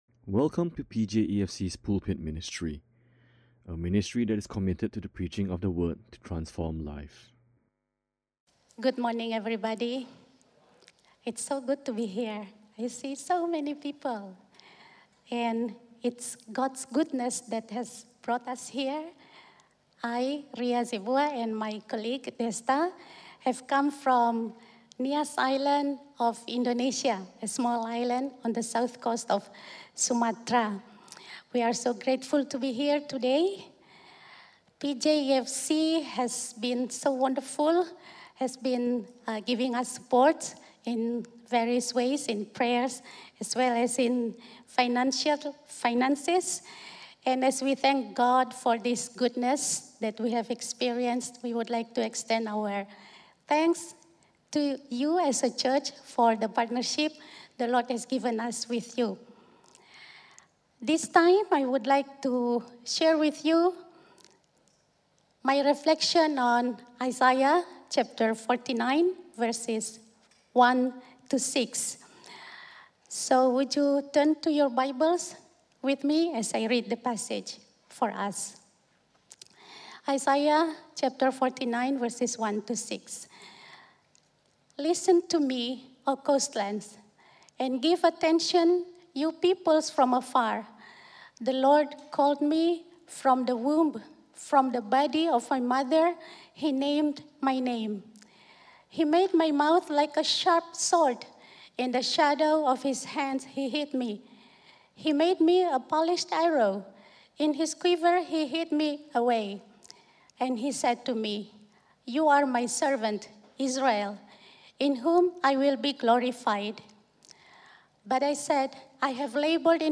This is a stand-alone sermon.